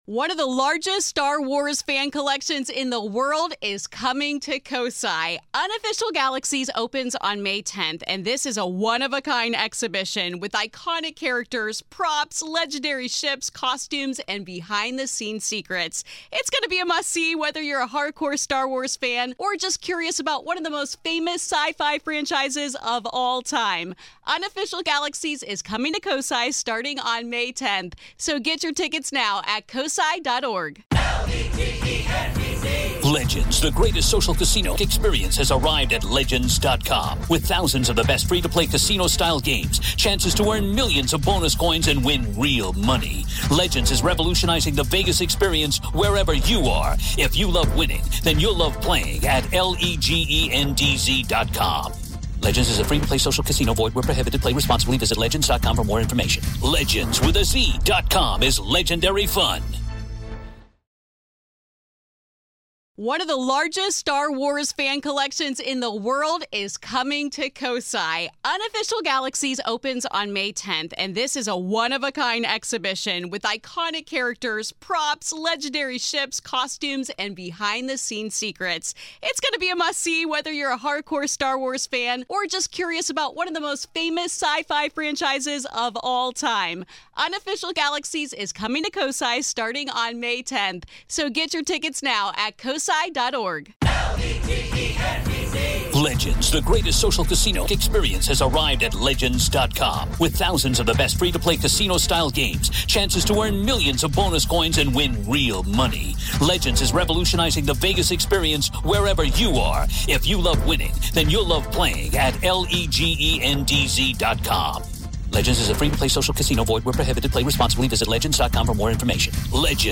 PART 2 - AVAILABLE TO GRAVE KEEPERS ONLY - LISTEN HERE In part two of our interview, available only to Grave Keepers , we discuss: Are locations likes Mansfield more haunted because of tourism?